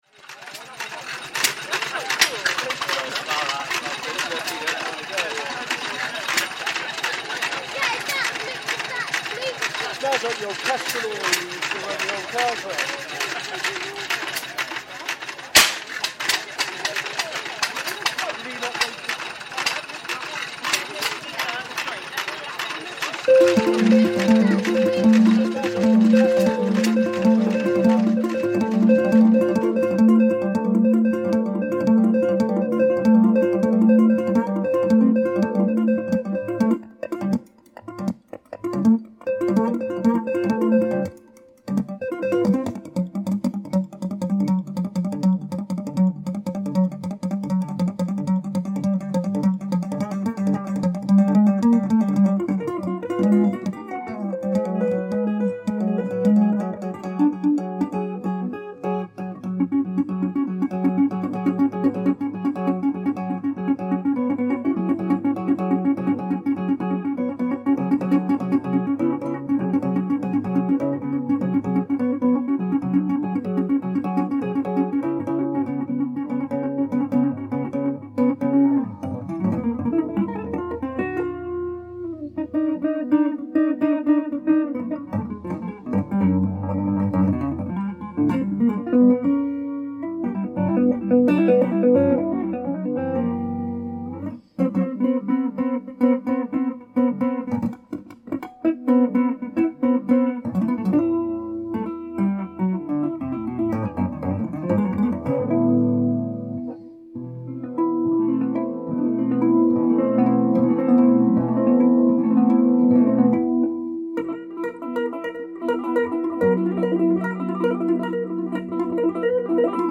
Steam engines at Coleford Festival of Transport reimagined